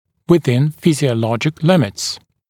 [wɪ’ðɪn ˌfɪzɪə’lɔʤɪk ‘lɪmɪts][уи’зин ˌфизиэ’лоджик ‘лимитс]в пределах физиологической нормы, физиологических ограничений